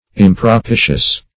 Search Result for " impropitious" : The Collaborative International Dictionary of English v.0.48: Impropitious \Im`pro*pi"tious\, a. Unpropitious; unfavorable.